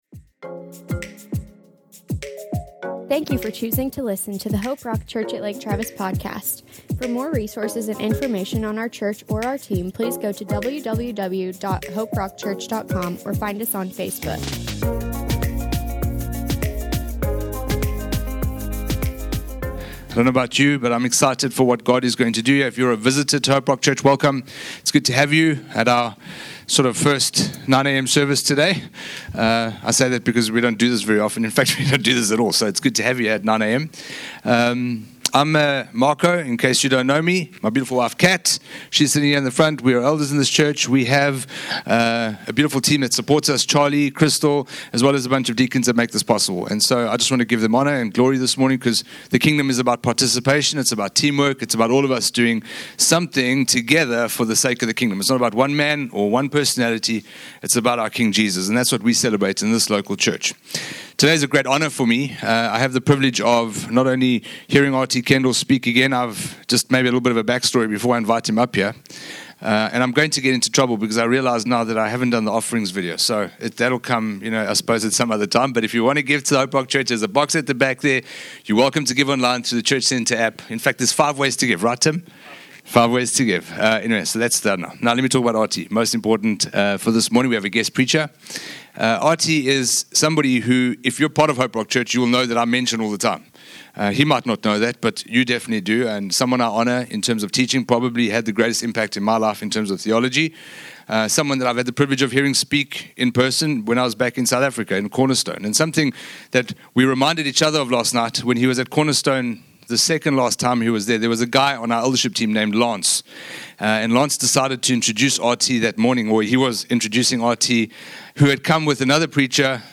RT Kendall ministered from Luke 1 and 18 on Are you ready for answered prayer?